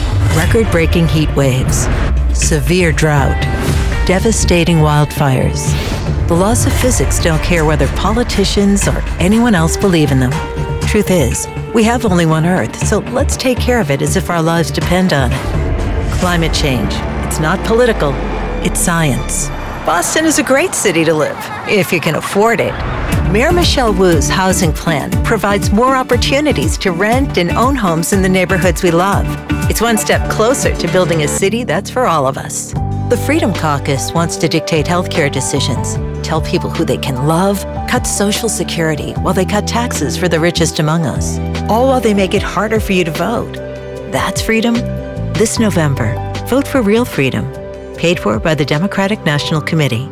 Political Ads
With a warm grounded (North American English) voice, wry smile and a storyteller’s heart, I want people to feel something real when they listen.
Acoustic Paneled and Sound Treated- 12'x6' recording studio and workstation